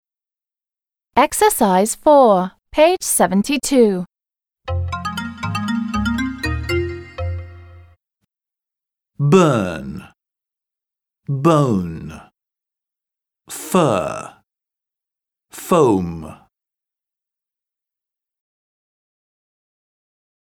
/з:/